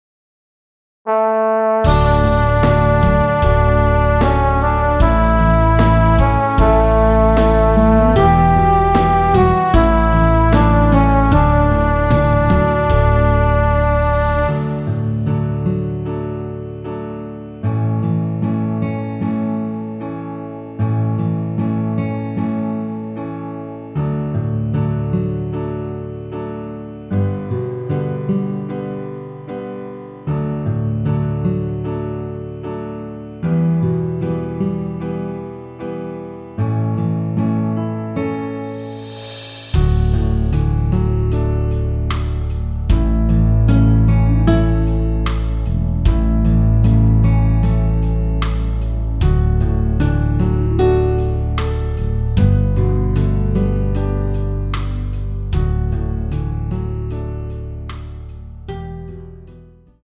원키에서(-5)내린 MR입니다.
앞부분30초, 뒷부분30초씩 편집해서 올려 드리고 있습니다.
중간에 음이 끈어지고 다시 나오는 이유는